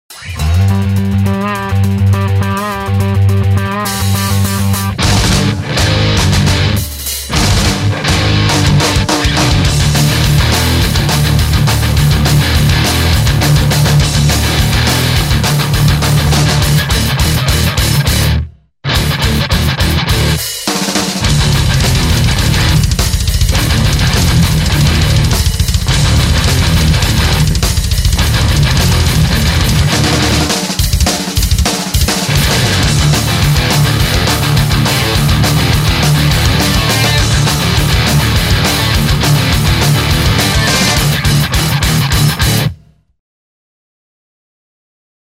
Misc. Amp Demo Clips